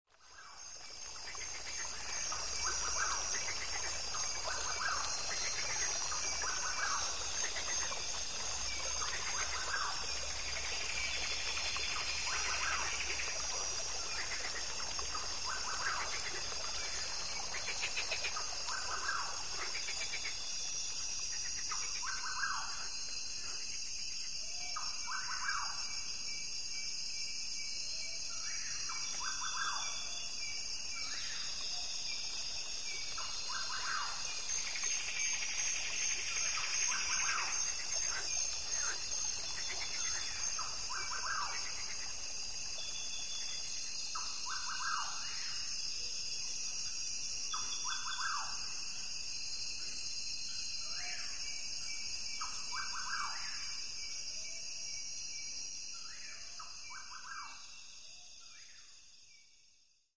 Download Rainforest sound effect for free.
Rainforest